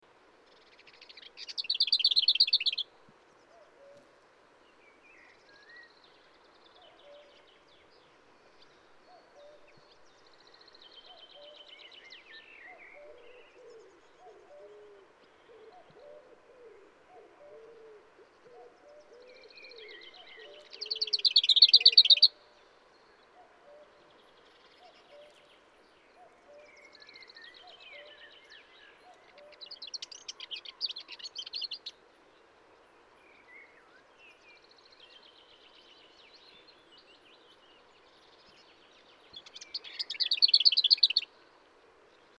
PFR03307, 090510, Lesser Whitethroat Sylvia currucca, plastic song